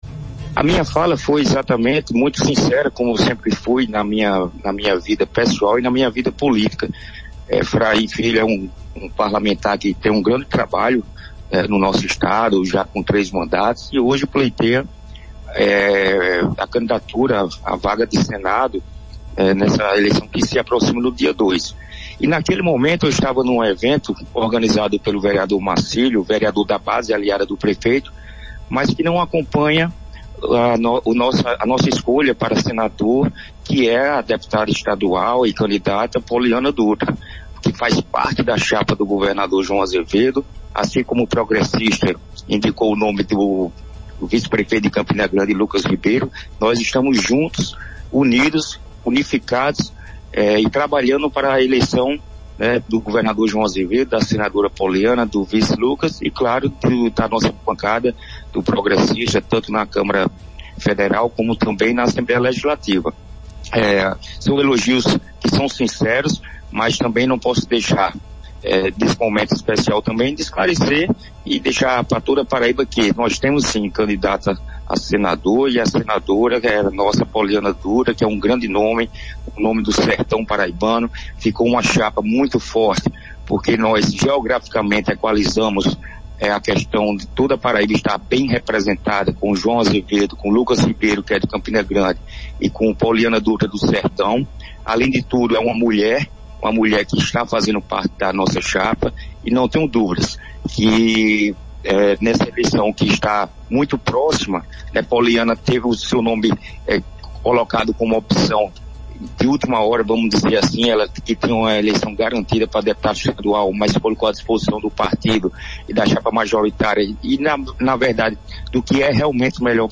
“Naquele momento eu estava num evento organizado por um vereador que não acompanha a nossa escolha para o Senado que é a deputada Pollyana Dutra, mas estamos juntos, unidos, e trabalhando para a eleição da senadora Pollyana, do governador João e da bancada do Progressistas. Nós temos sim candidata a senadora”, esclareceu em entrevista ao programa Arapuan Verdade.